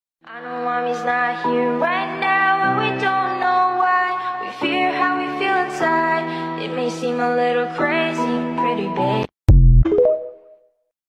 You Just Search Sound Effects And Download. tiktok hahaha sound effect Download Sound Effect Home